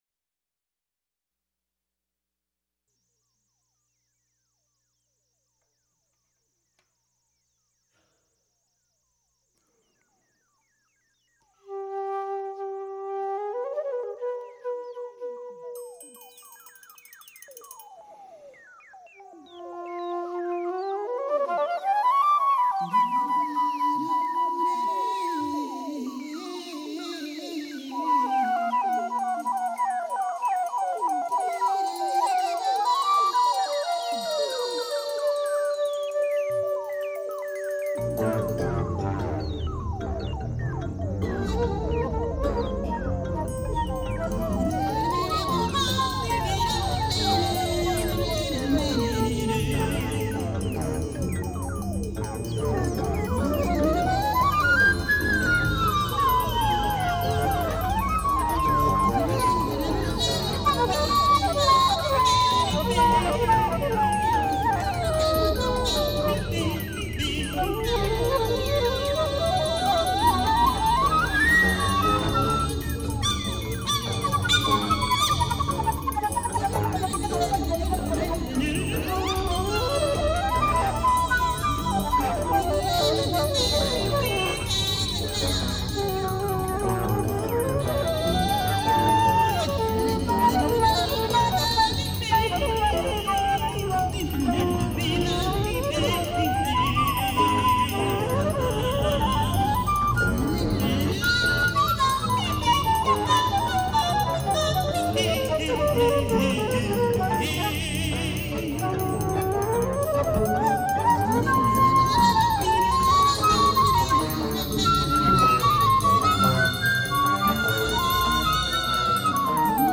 flute, tenor saxophone, synthesizers
trumpet
harp
baritone saxophone
double bass
drums, percussion